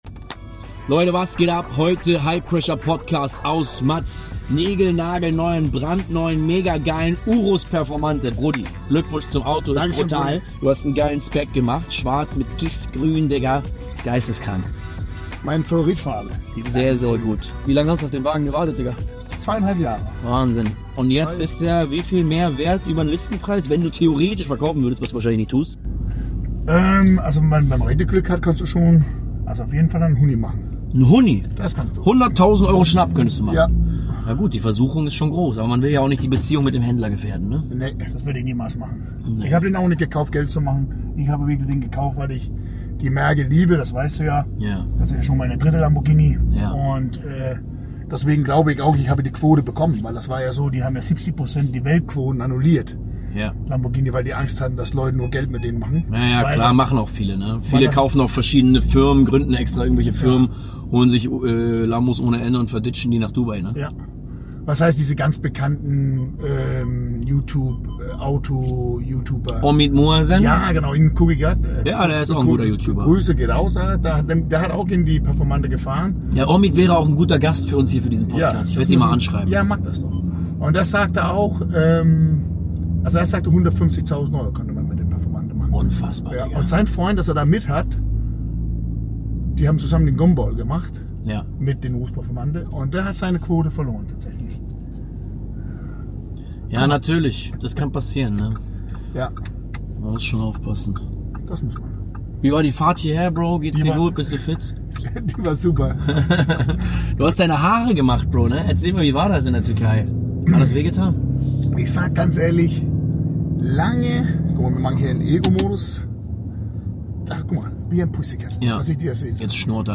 Eine neue Folge aus dem brandneuen Lamborghini Urus